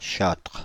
Ääntäminen
Etsitylle sanalle löytyi useampi kirjoitusasu: châtres châtrés Ääntäminen France (Île-de-France): IPA: /ʃɑtʁ/ Haettu sana löytyi näillä lähdekielillä: ranska Käännöksiä ei löytynyt valitulle kohdekielelle.